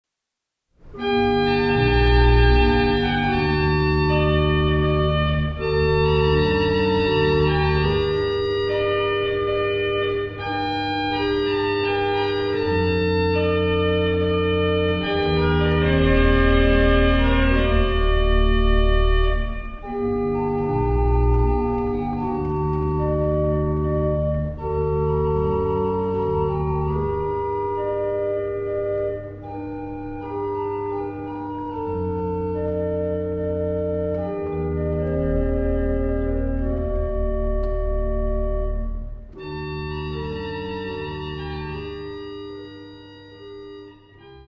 Australian classical music